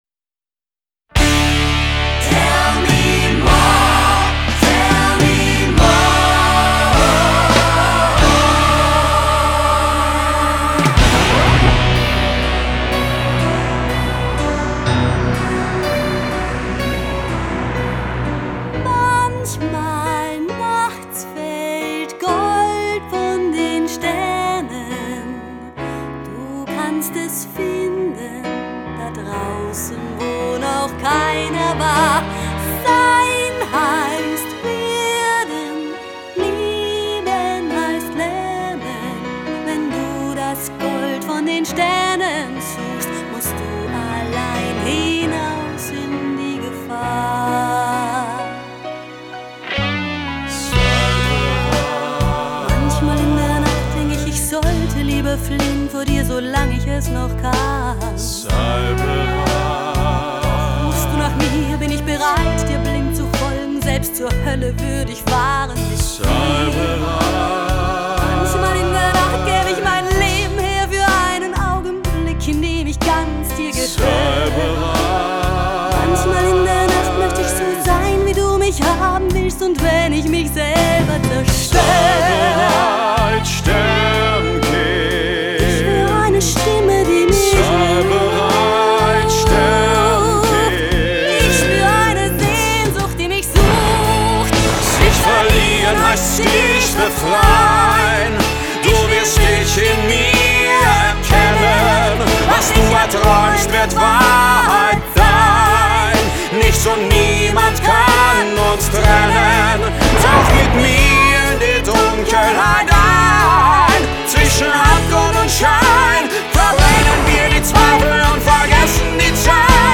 Musical Show - Hit Medley (mp3)